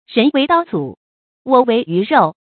注音：ㄖㄣˊ ㄨㄟˊ ㄉㄠ ㄗㄨˇ ，ㄨㄛˇ ㄨㄟˊ ㄧㄩˊ ㄖㄡˋ
人為刀俎，我為魚肉的讀法